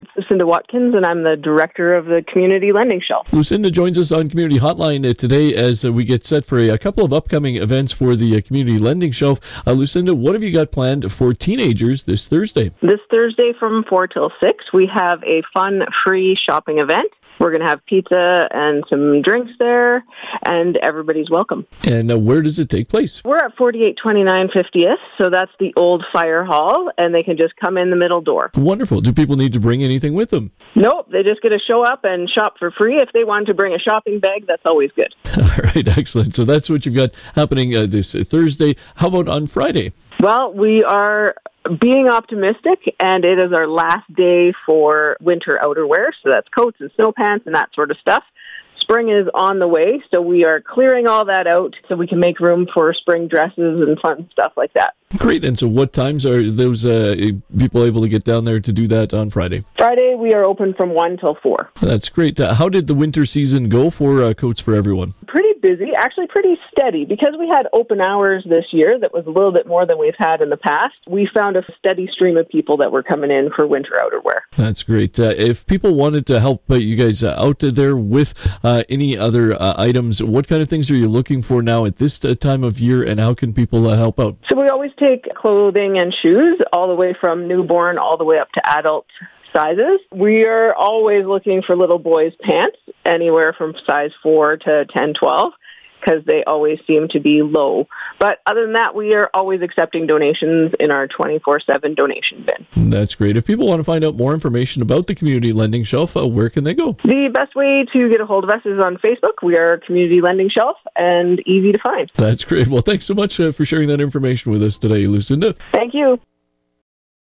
96.5 The Ranch’s Community Hotline conversation